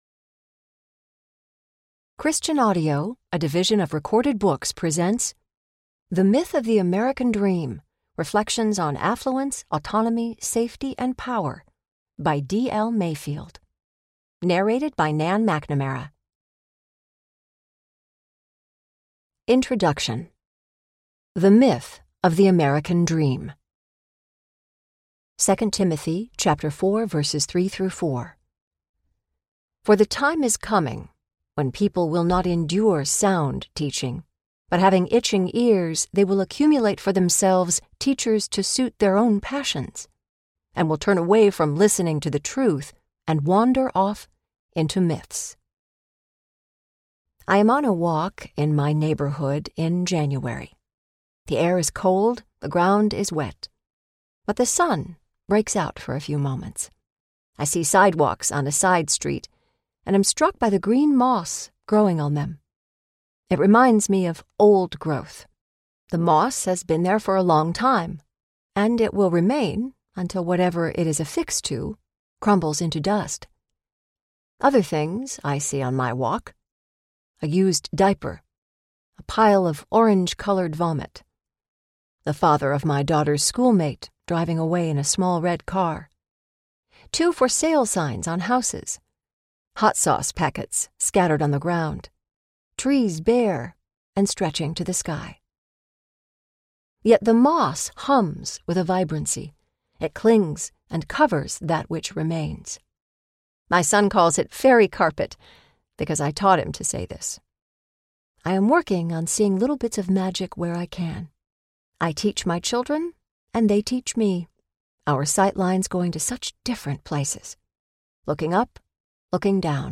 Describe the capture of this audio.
7.0 Hrs. – Unabridged